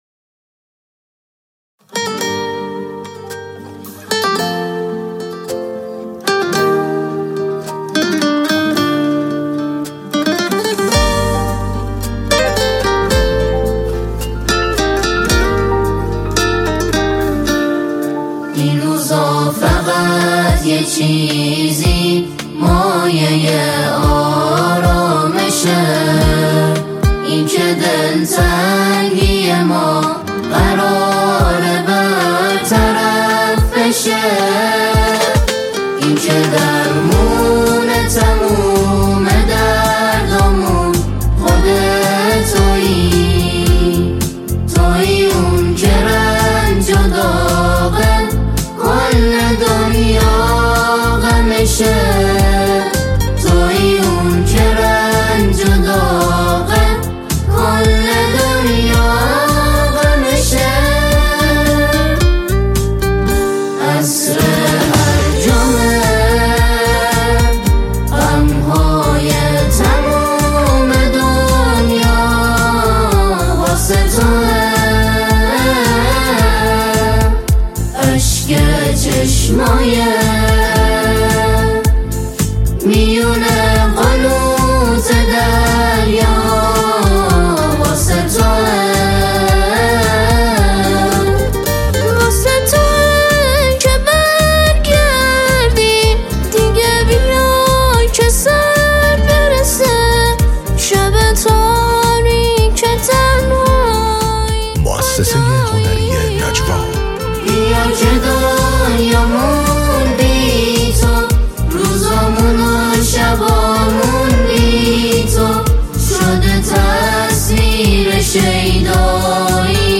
ژانر: سرود ، سرود مذهبی ، سرود مناسبتی